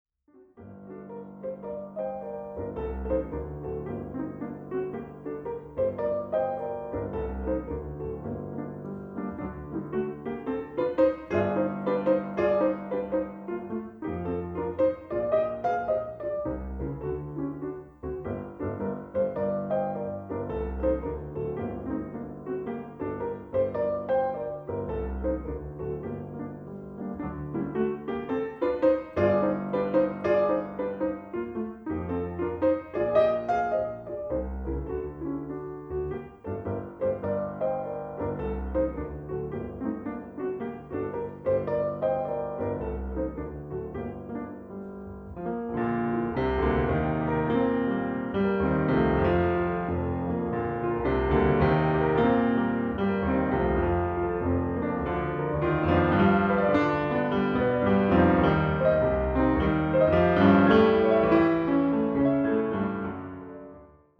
Genre : Classique